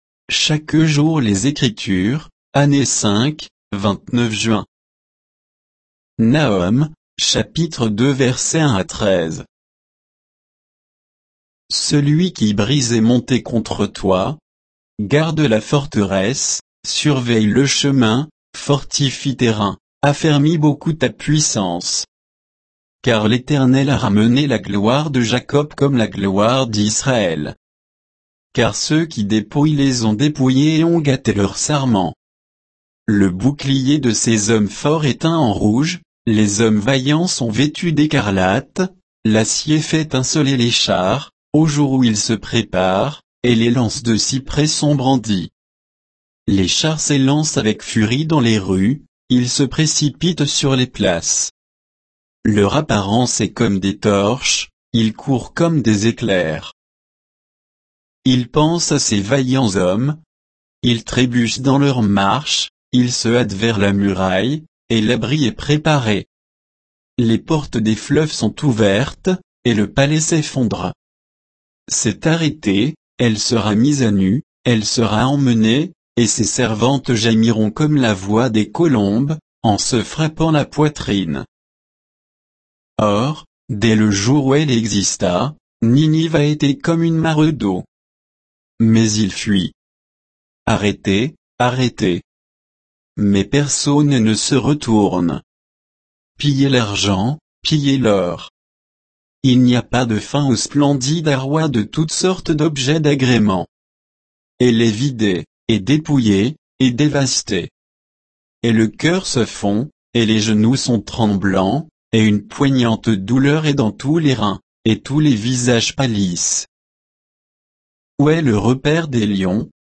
Méditation quoditienne de Chaque jour les Écritures sur Nahum 2